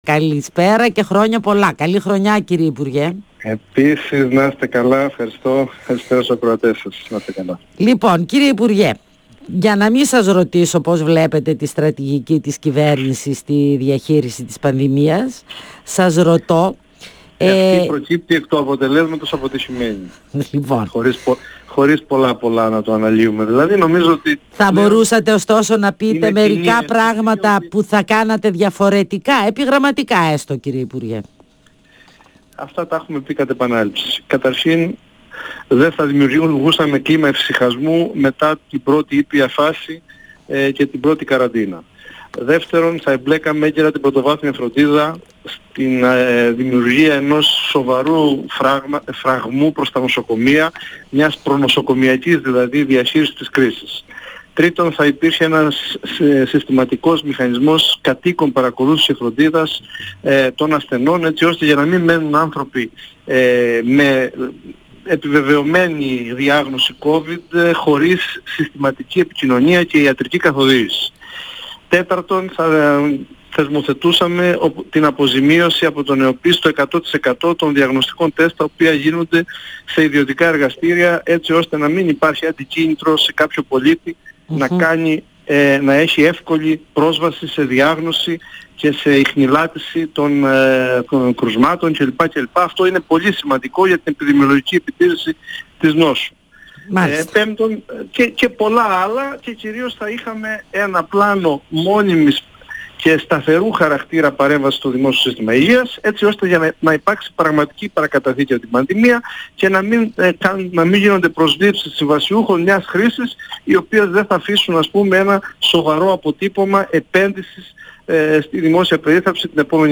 O πρώην υπουργός υγείας του ΣΥΡΙΖΑ, Ανδρέας Ξανθός, στον 102 fm της ΕΡΤ3
Συνεντεύξεις